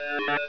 ALARM-00.WAV